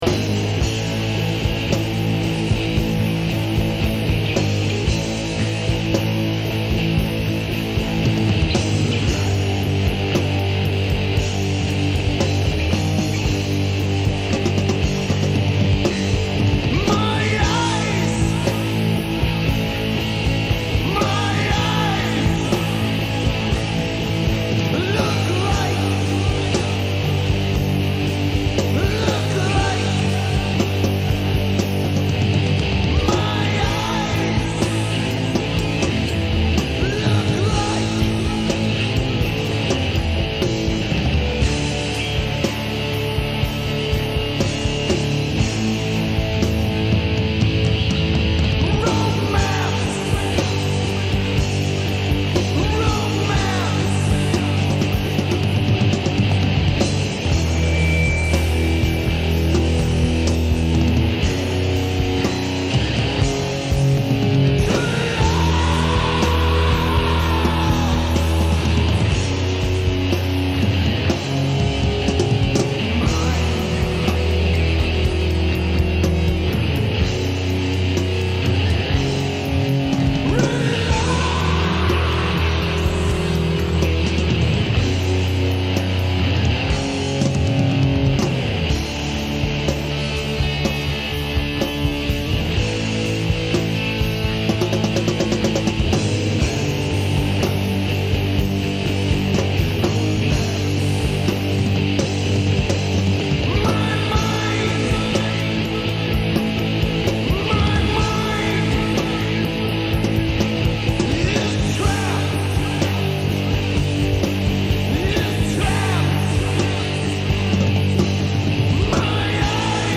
Guitar/Vocals
Bass
Drums Filed under: Metal , Rock